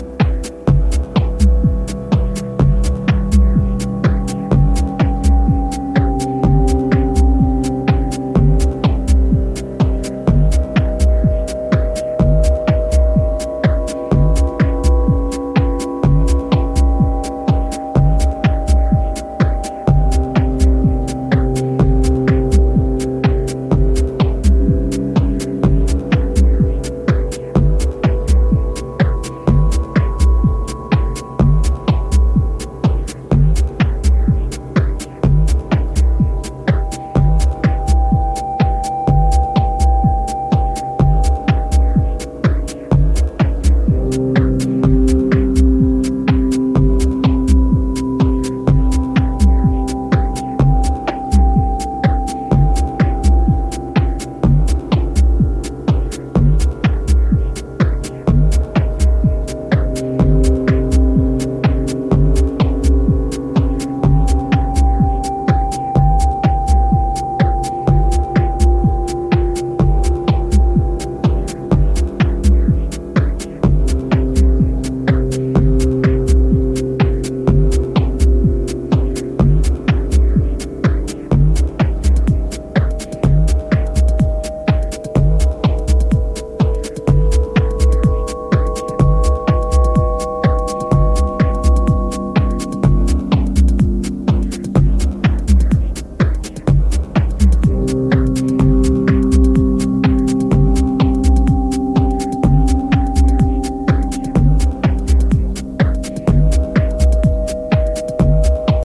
Electronix Techno Ambient